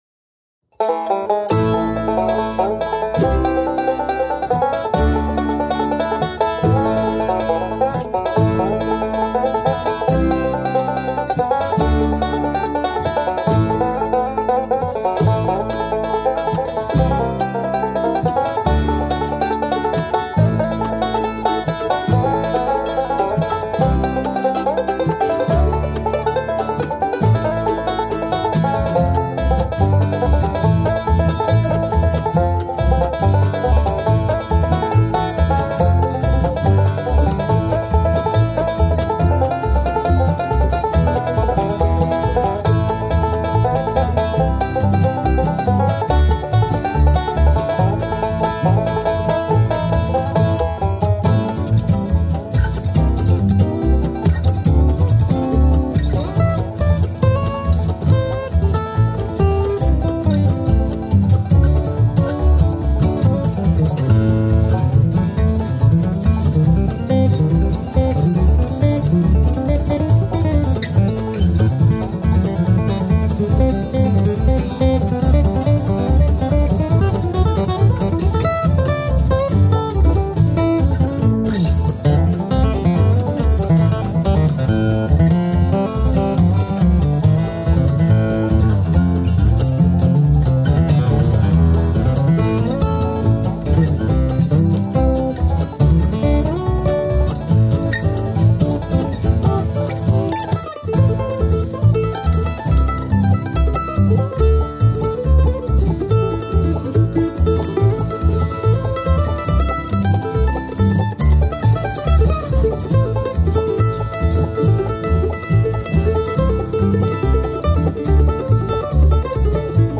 bluegrass original tune
But I made some CDs and so recently I loaded the songs back on Protools so I could alter the sound somewhat. I added a little depth and eq.